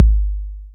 808-Kicks26.wav